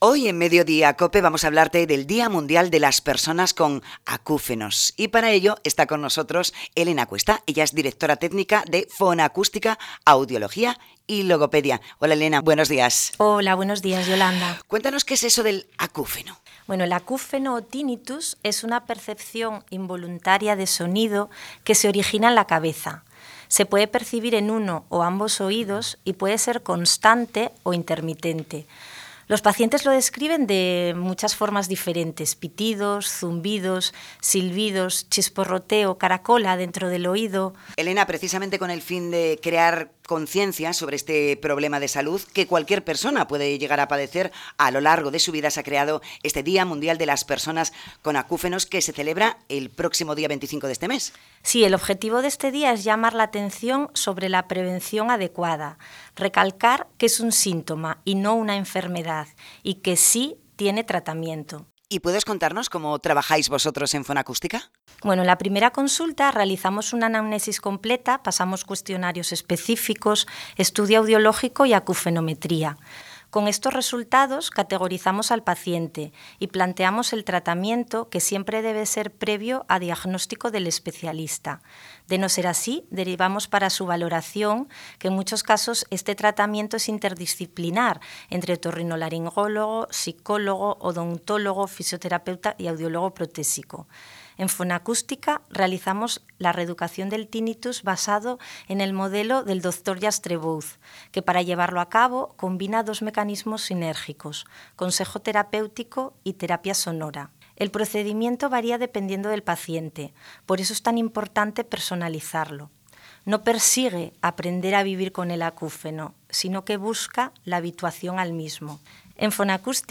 en Cope Asturias hablando sobre los Acúfenos